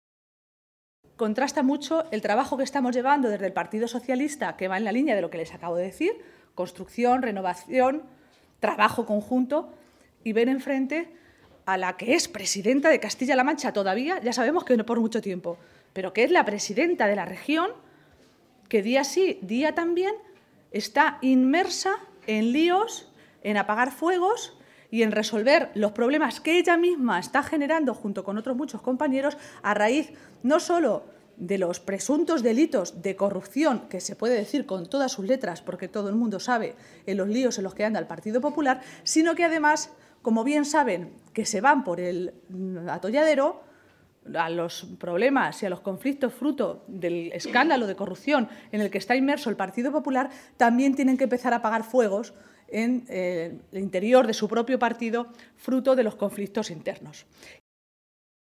Maestre se pronunciaba de esta manera esta tarde, en Ciudad Real, minutos antes de que comenzara la reunión de la ejecutiva regional socialista, presidida por García-Page en esa ciudad manchega.